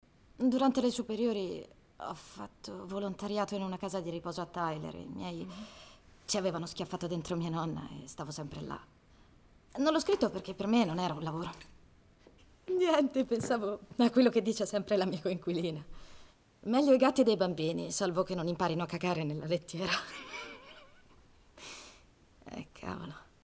nel film "Qualcosa di buono", in cui doppia Emmy Rossum.